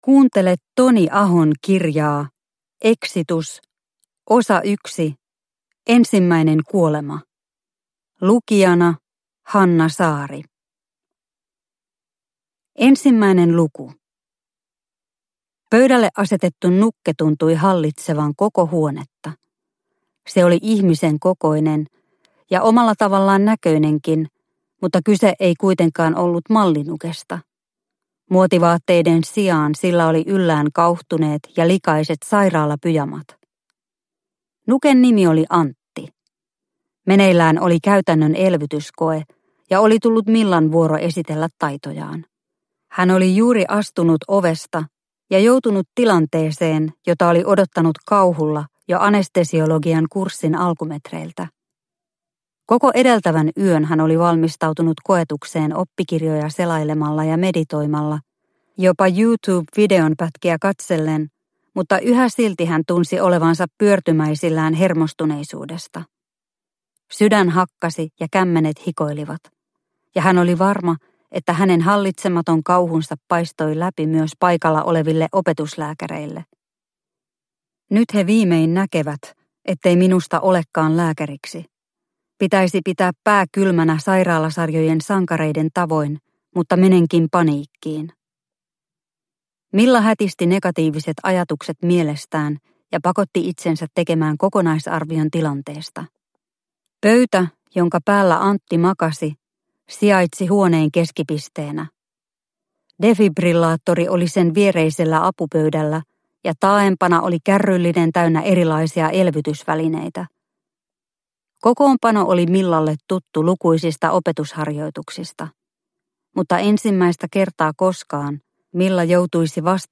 Exitus – Ljudbok – Laddas ner